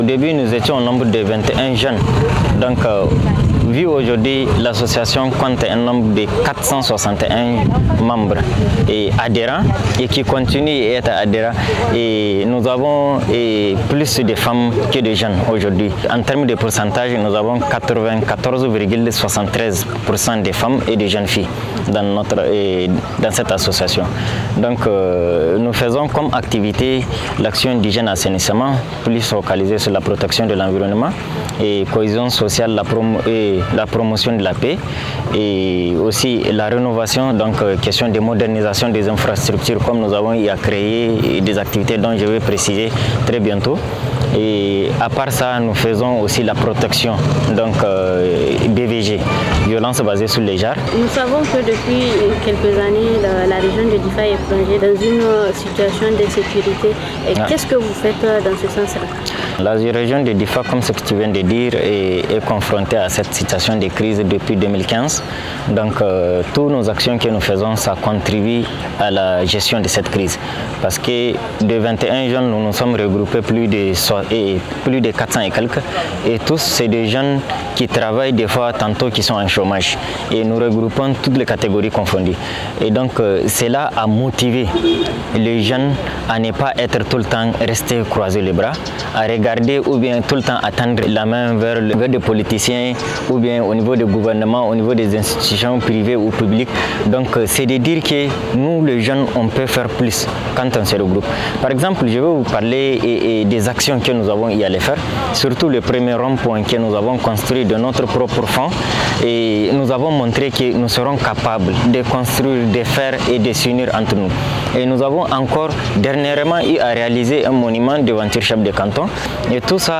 FR Interview https